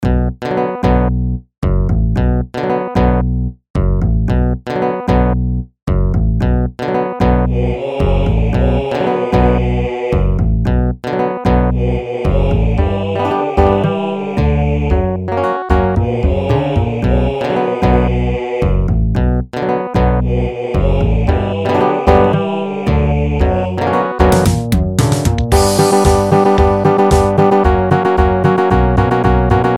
I started off by making a really basic midi version of the song by putting down the bass line, and then adding the guitar, and what would be the vocal melody.